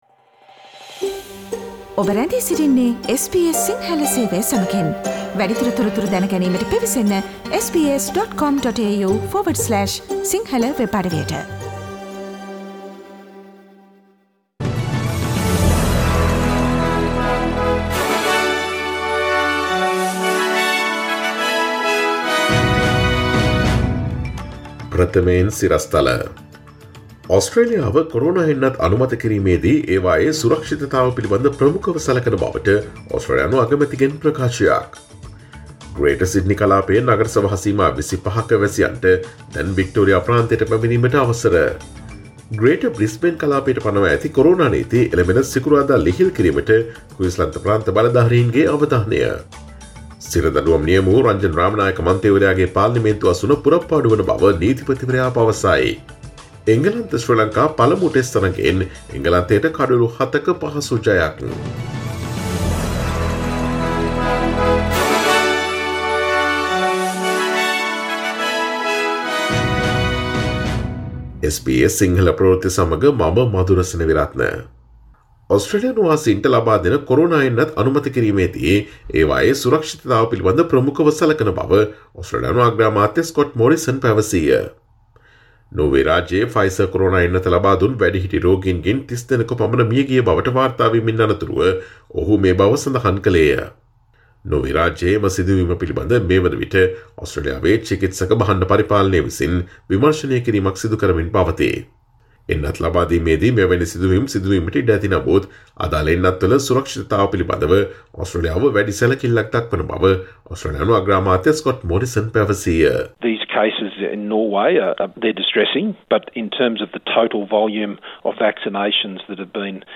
Today’s news bulletin of SBS Sinhala radio – Tuesday19 January 2021